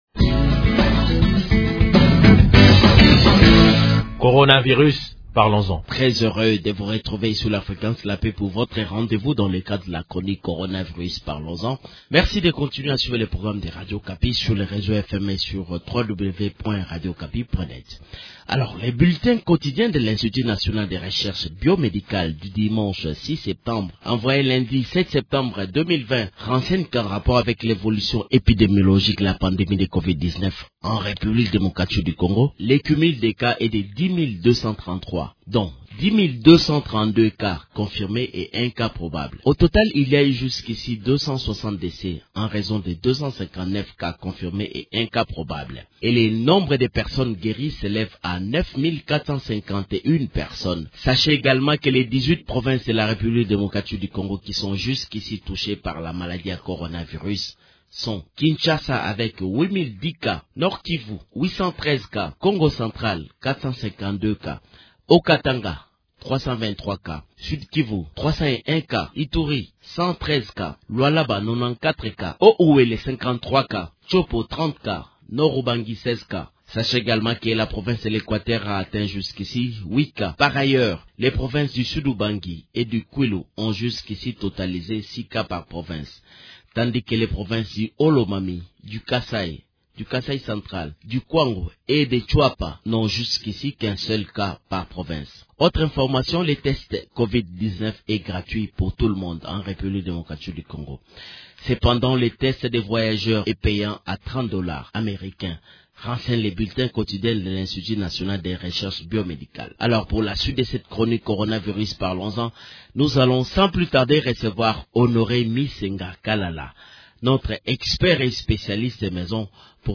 En prévision de cet événement qui tombe ce jour, une table ronde a été organisée la veille dans le cadre du partenariat entre l’Unesco et le gouvernement, autour de l’alphabétisation et le coronavirus.